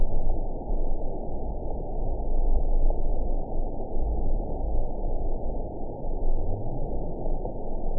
event 915245 date 11/25/22 time 11:23:06 GMT (3 years ago) score 6.48 location TSS-AB09 detected by nrw target species NRW annotations +NRW Spectrogram: Frequency (kHz) vs. Time (s) audio not available .wav